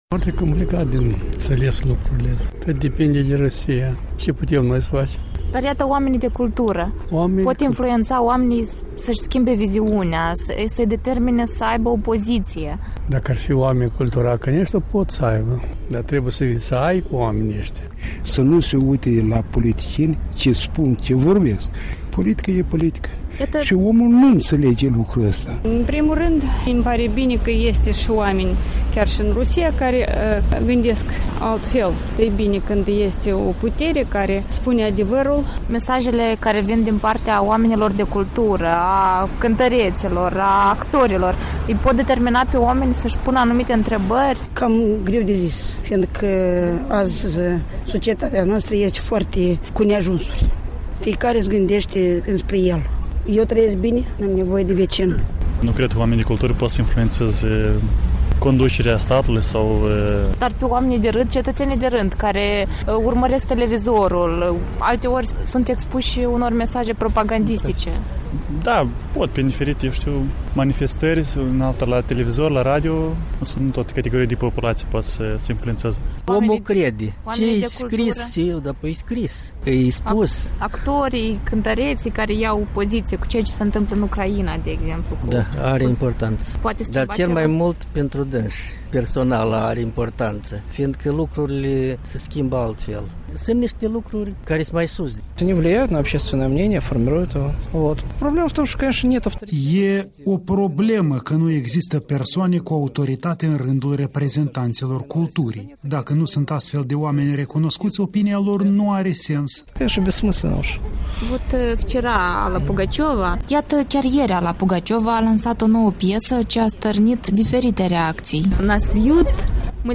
Sondaj de opinie pe străzile Chișinăului